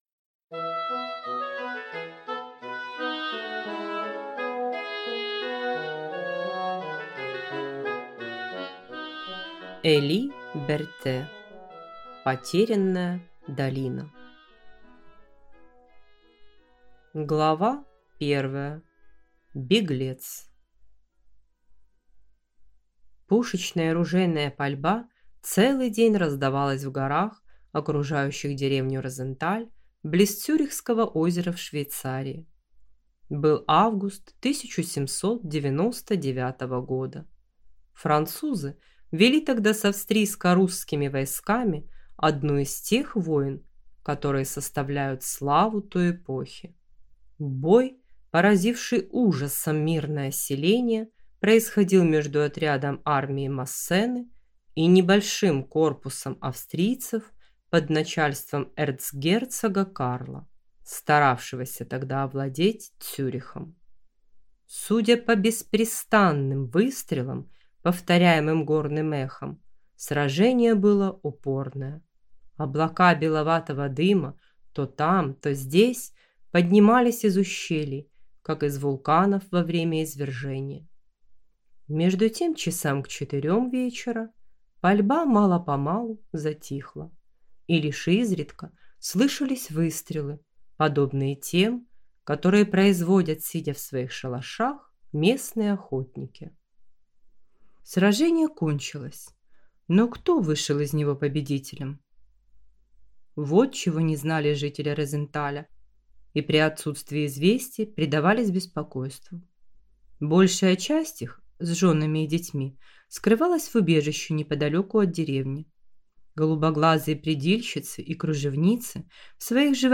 Аудиокнига Потерянная долина | Библиотека аудиокниг